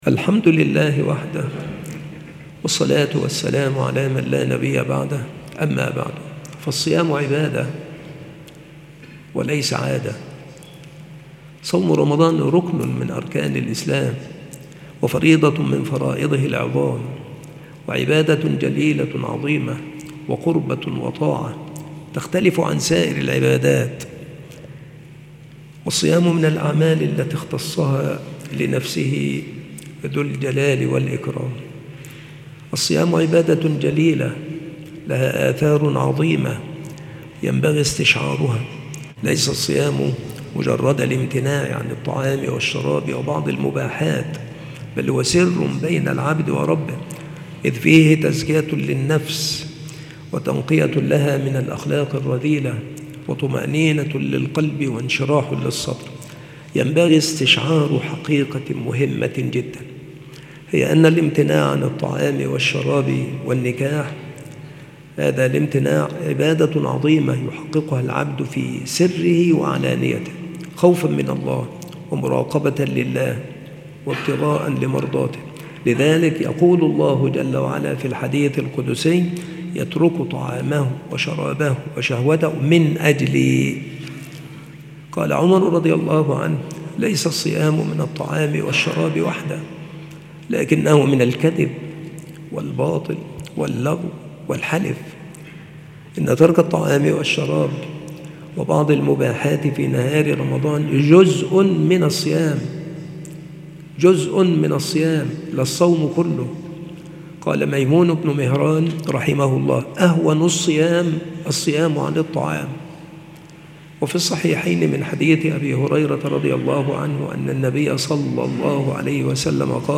السلسلة مواعظ وتذكير
مكان إلقاء هذه المحاضرة بالمسجد الشرقي - سبك الأحد - أشمون - محافظة المنوفية - مصر